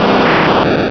sound / direct_sound_samples / cries / arbok.wav
arbok.wav